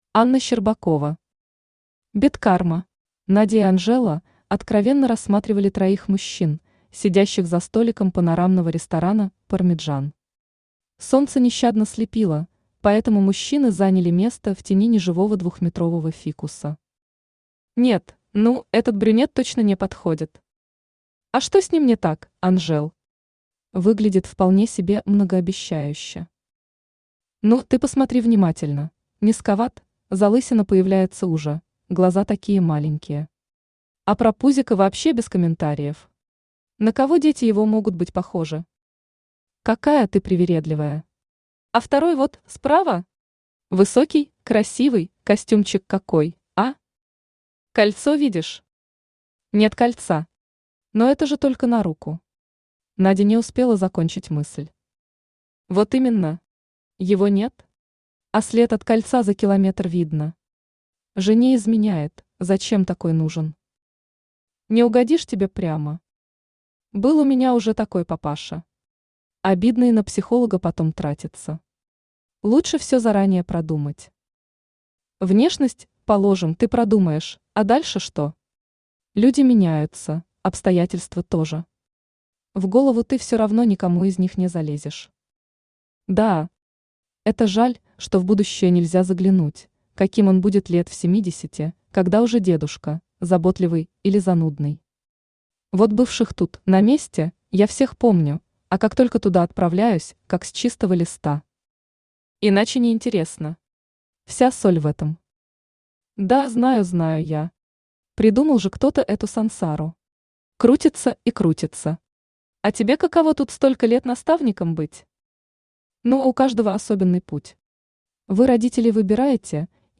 Аудиокнига Бедкарма | Библиотека аудиокниг